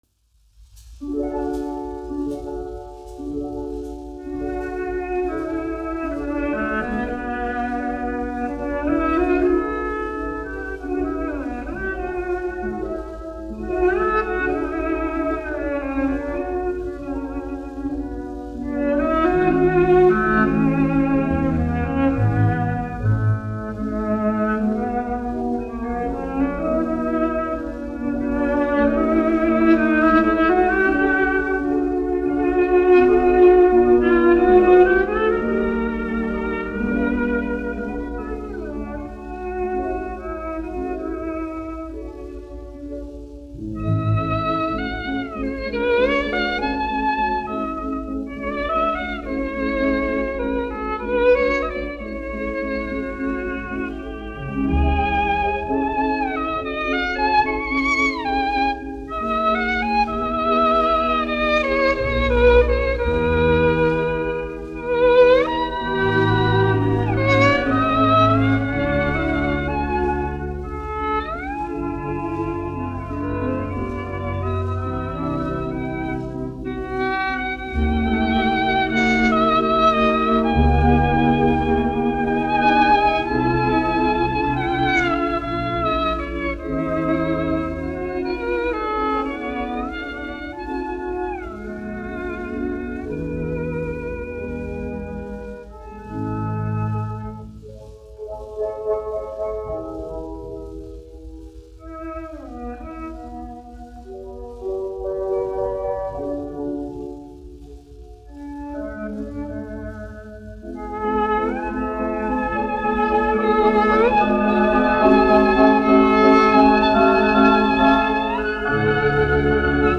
1 skpl. : analogs, 78 apgr/min, mono ; 25 cm
Orķestra mūzika
Latvijas vēsturiskie šellaka skaņuplašu ieraksti (Kolekcija)